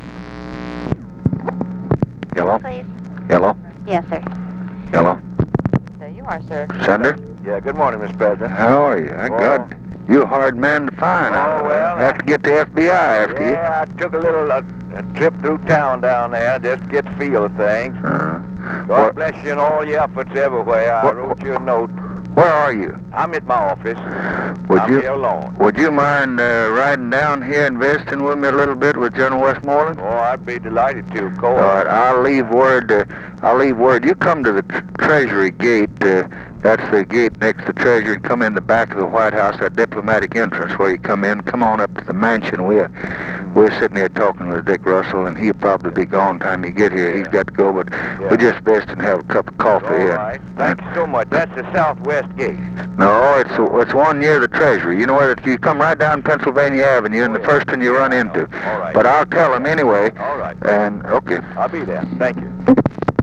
Conversation with JOHN STENNIS, April 6, 1968
Secret White House Tapes